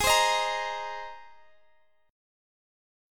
Listen to Abadd9 strummed